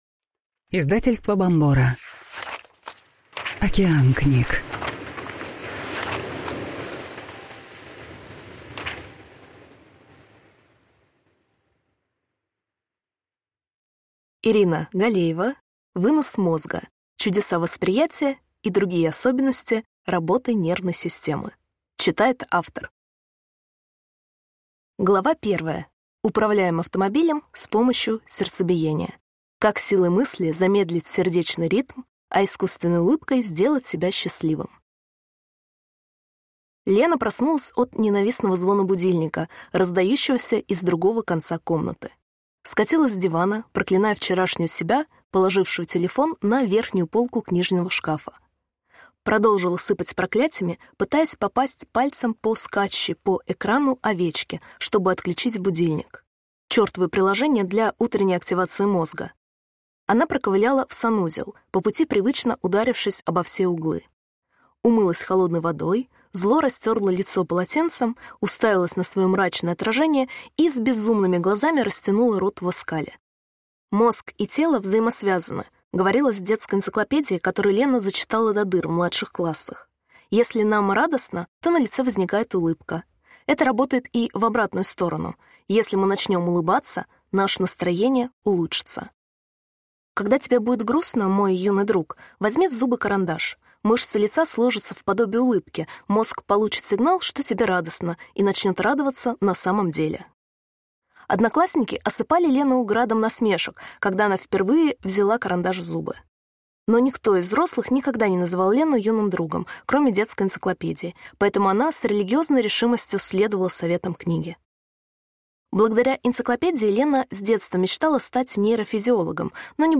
Аудиокнига Вынос мозга. Чудеса восприятия и другие особенности работы нервной системы | Библиотека аудиокниг